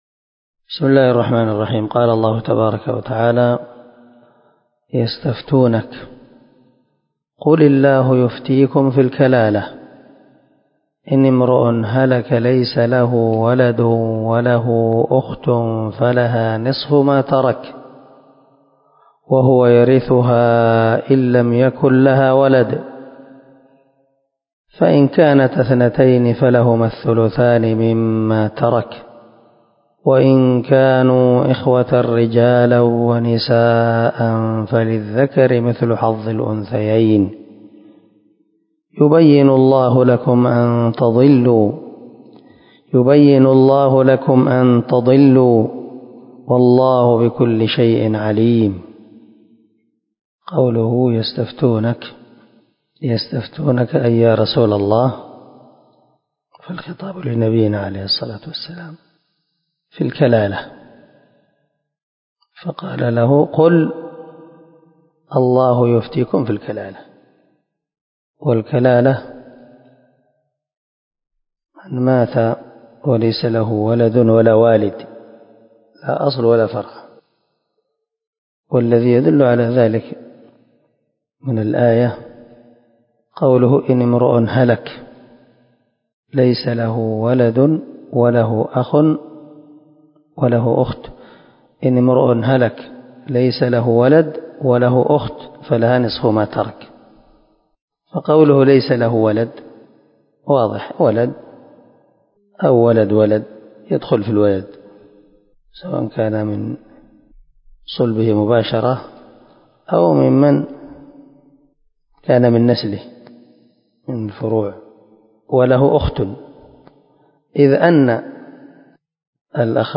333الدرس 101 تفسير آية ( 176 ) من سورة النساء من تفسير القران الكريم مع قراءة لتفسير السعدي